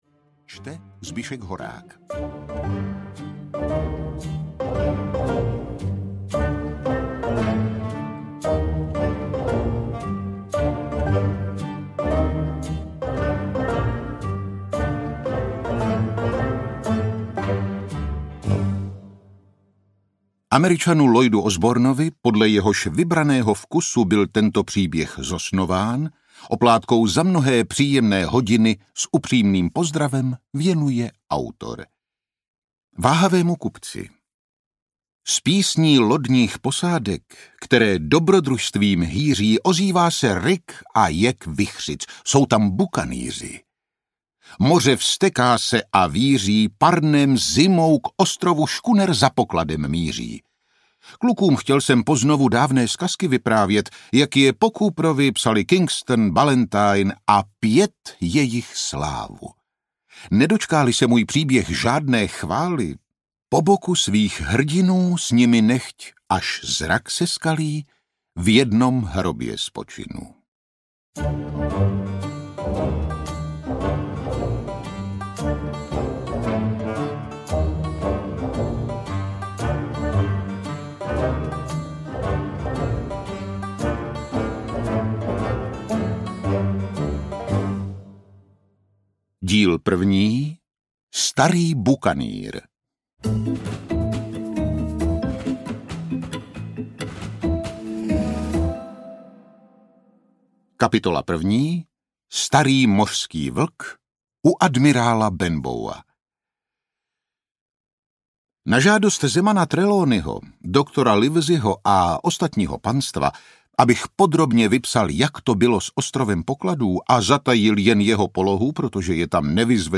Ostrov pokladů audiokniha
Ukázka z knihy
Vyrobilo studio Soundguru.